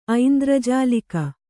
♪ aindrajālika